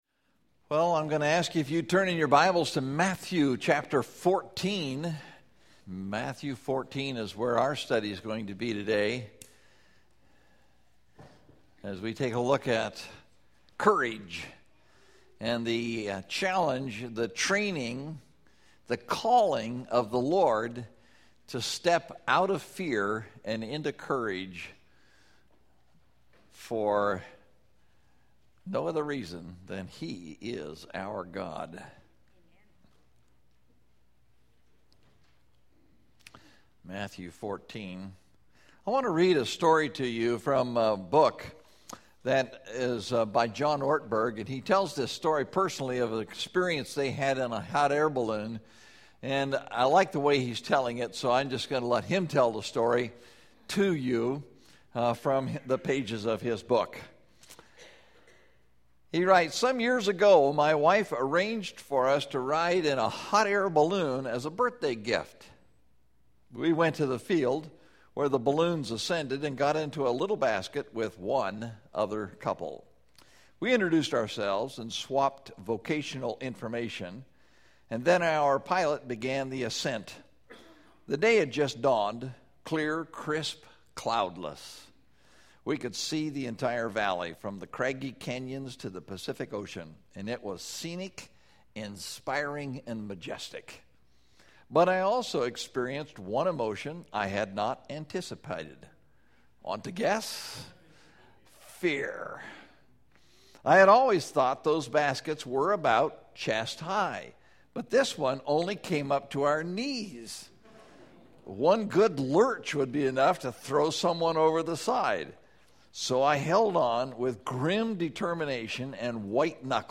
Living Life Courageously (Matthew 14:1-36) – Mountain View Baptist Church